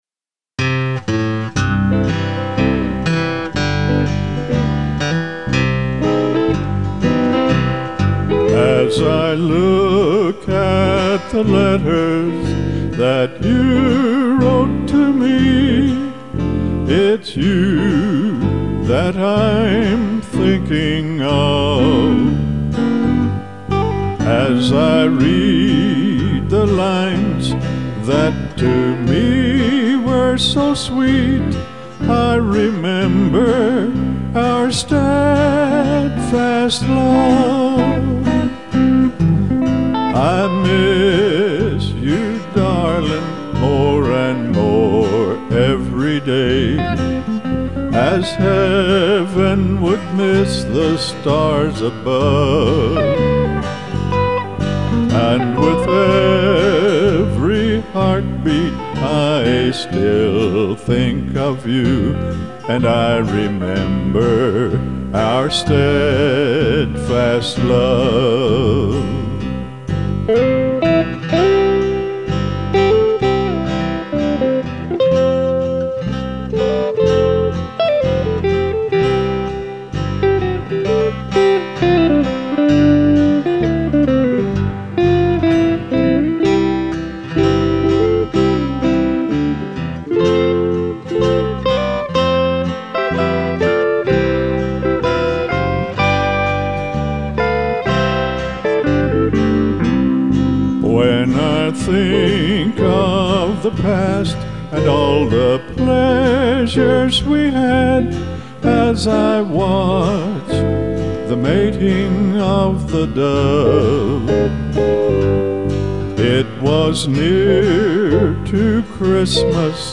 vocals on all songs, except:
all instruments